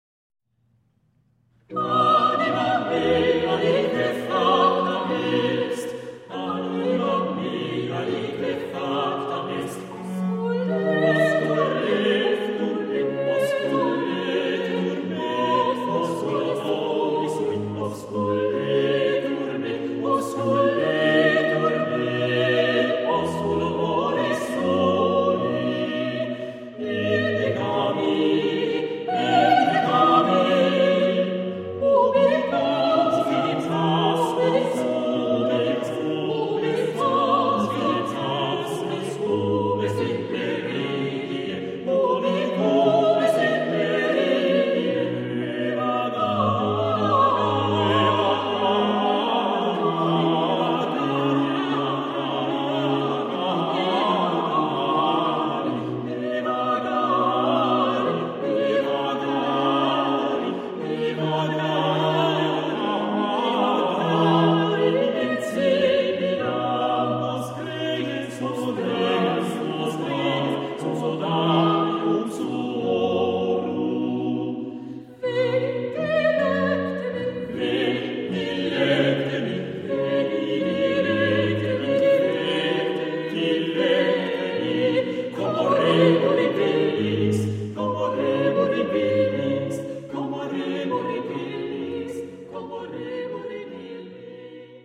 soprano
alto
tenor
bass
baroque violoncello
harpsichord/organ